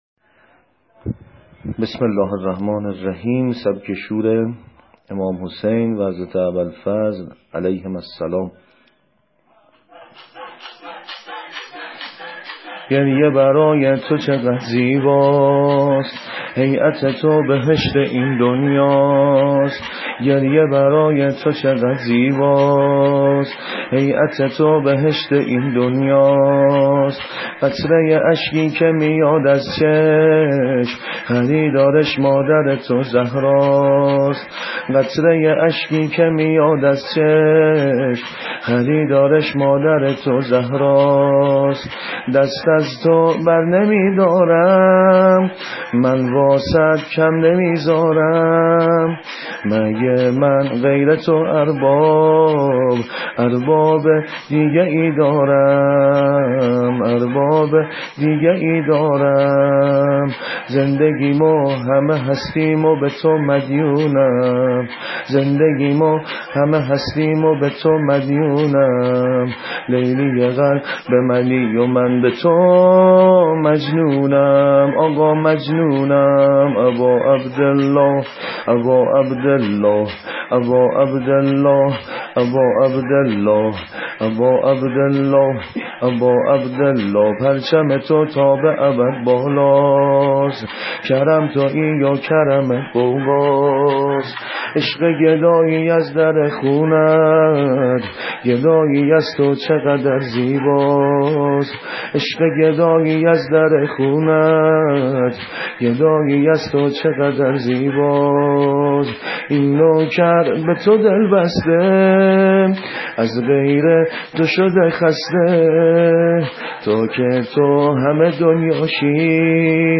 زمینه امام حسین(ع) -( این دل بازم تنگه واسه صحن وسرات آقا )